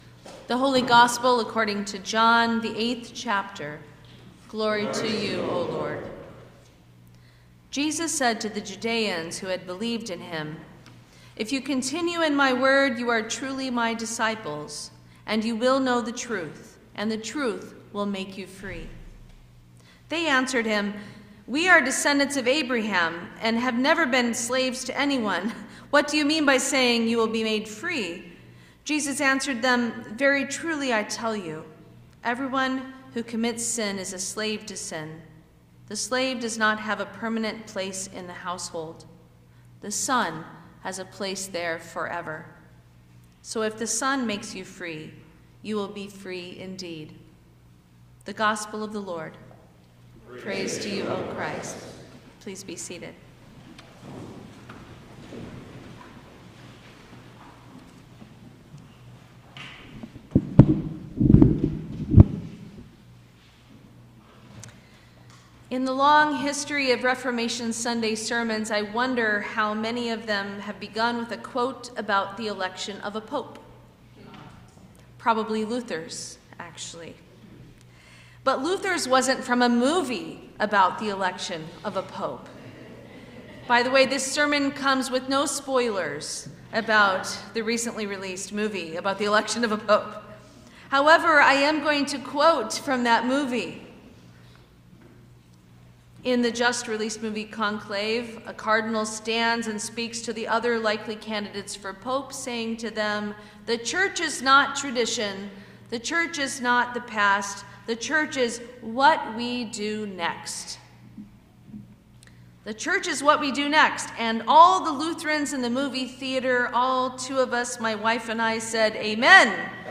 Sermon for Reformation Sunday 2024